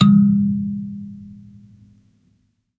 kalimba_bass-G#2-pp.wav